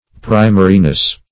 Search Result for " primariness" : The Collaborative International Dictionary of English v.0.48: Primariness \Pri"ma*ri*ness\, n. The quality or state of being primary, or first in time, in act, or in intention.